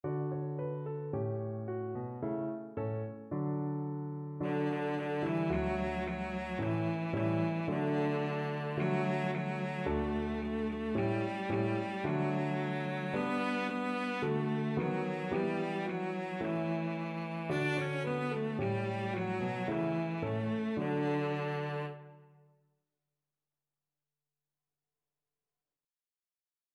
Childrens Childrens Cello Sheet Music Diddle Diddle Dumpling
Cello
Traditional Music of unknown author.
Fast =c.110
D4-D5
2/4 (View more 2/4 Music)
D major (Sounding Pitch) (View more D major Music for Cello )